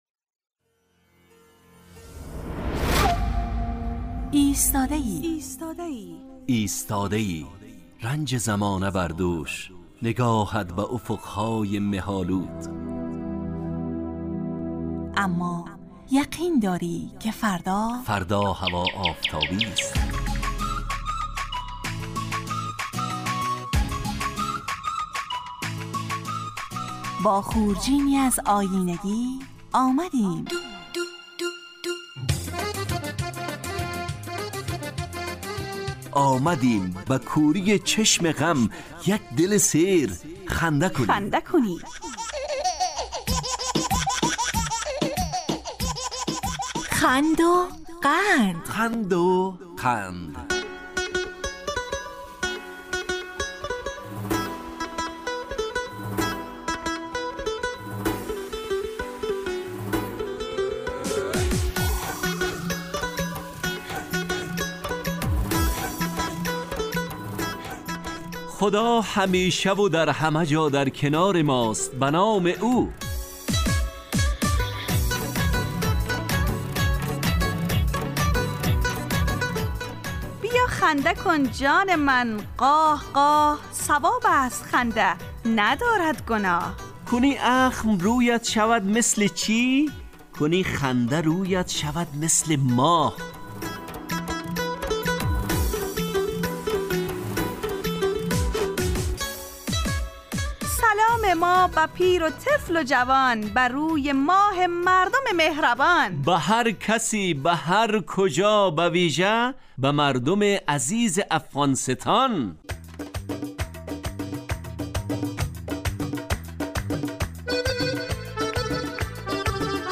خند وقند برنامه ای طنز در قالب ترکیبی نمایشی است که هرجمعه به مدت 35 دقیقه در ساعت 9:15 به وقت ایران و 10:15 به وقت افغانستان از رادیو دری پخش میگردد.